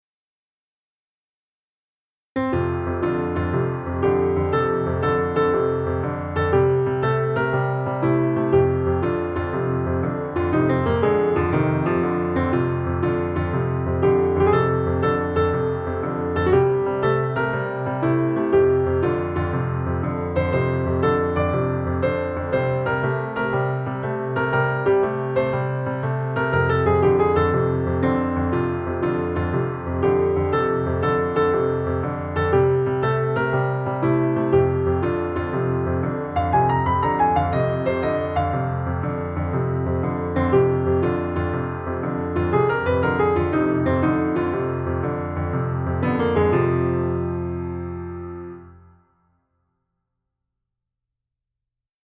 Blues Version